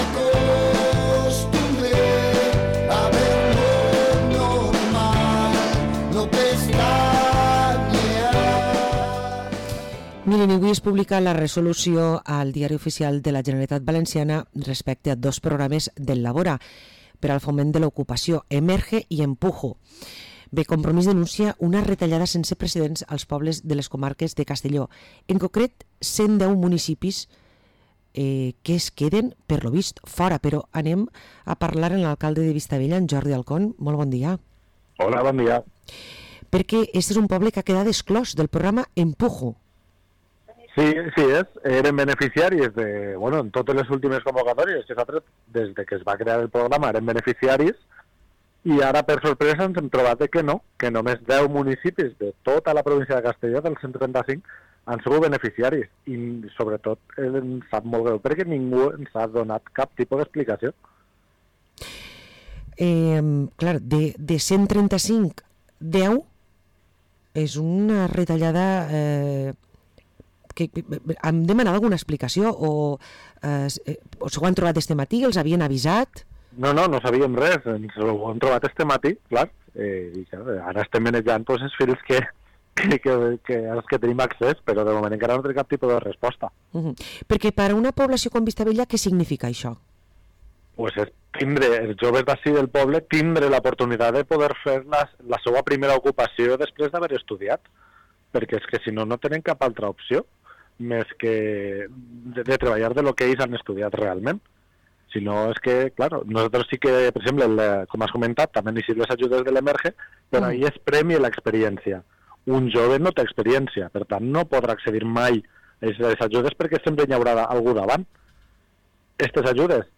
Parlem amb Jordi Alcon, alcalde de Vistabella del Maestrat